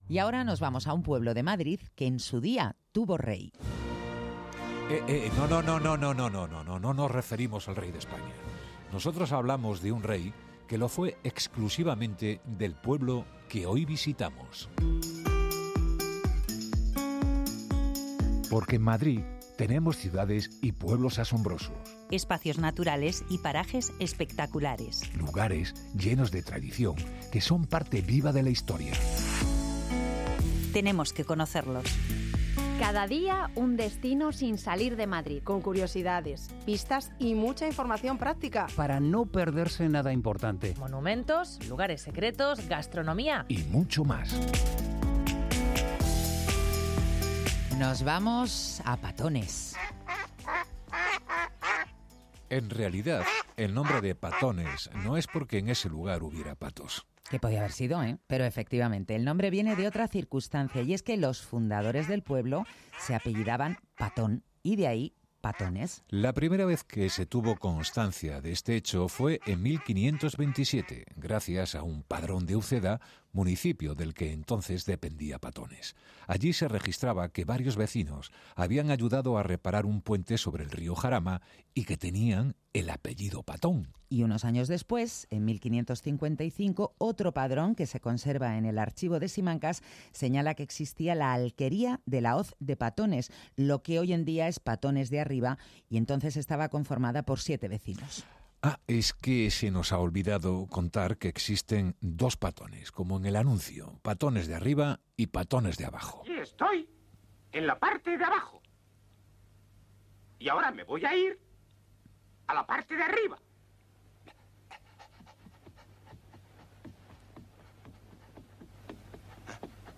Dos periodistas